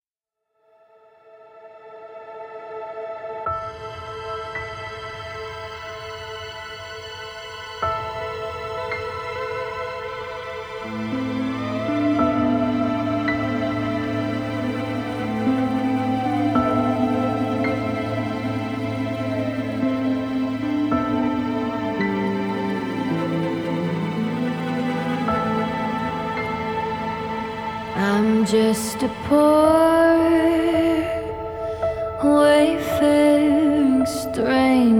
# Американа